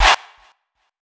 TS Chant 2.wav